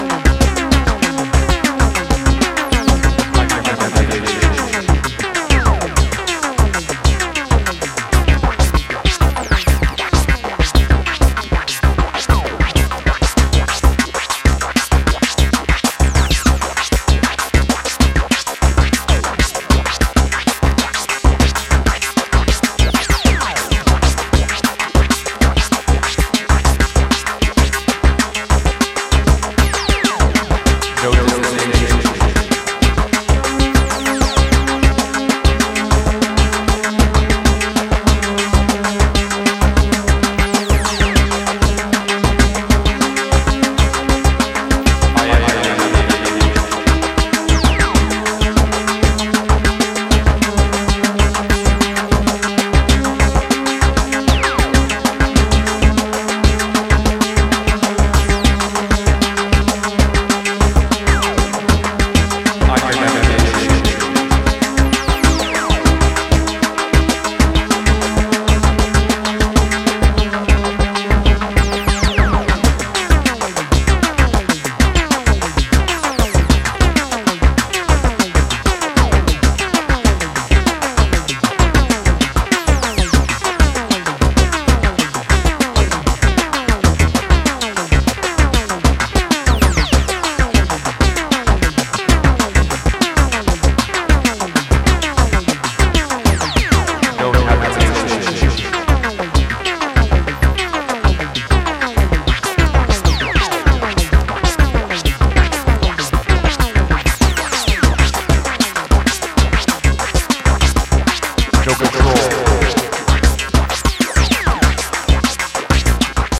recorded live in studio sessions